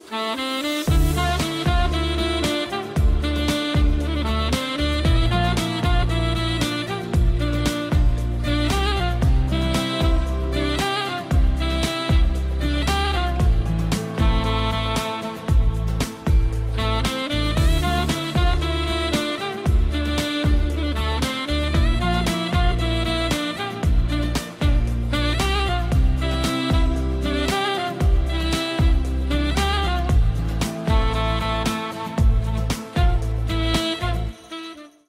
Поп Музыка
кавер # без слов